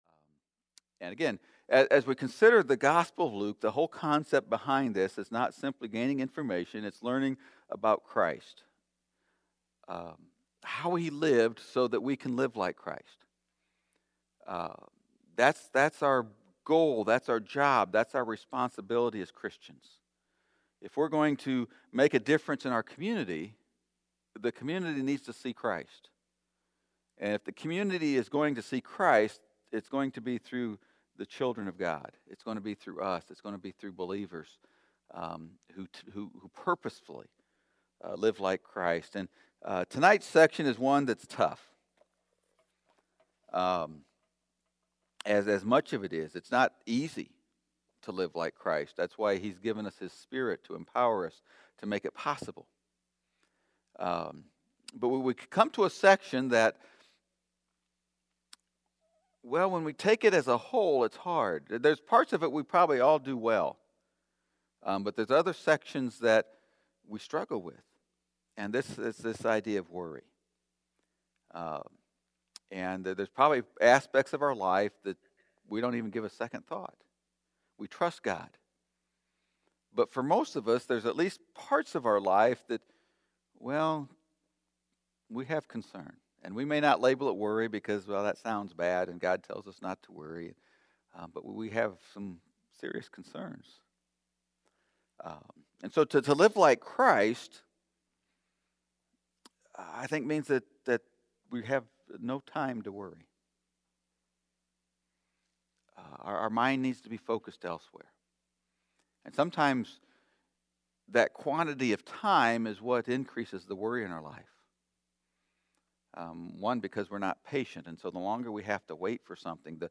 Sermon from the “Living Like Christ” series.